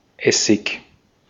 Ääntäminen
Ääntäminen US UK Tuntematon aksentti: IPA : /ˈvɪ.nɪ.ɡɜː/ Haettu sana löytyi näillä lähdekielillä: englanti Käännös Ääninäyte Substantiivit 1.